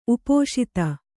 ♪ upōṣita